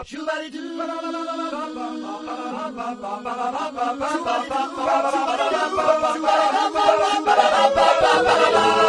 Bop Sound Effects MP3 Download Free - Quick Sounds